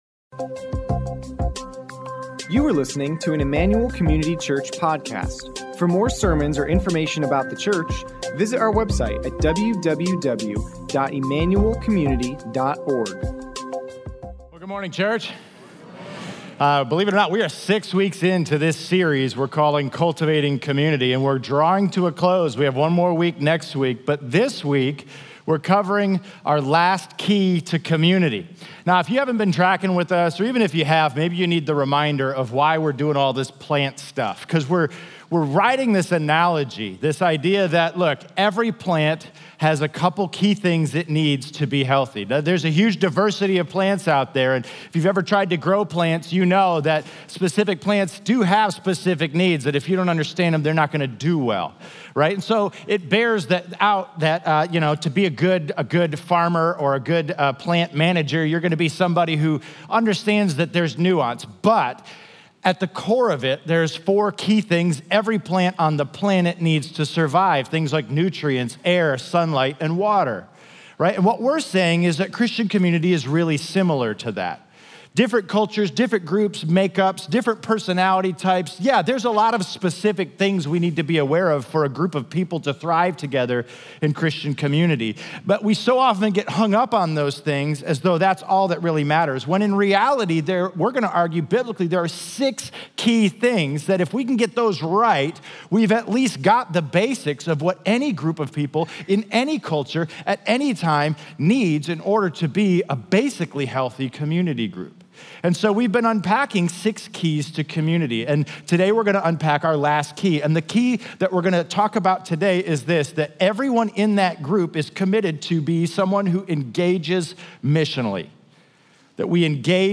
Sermon Notes Completed Notes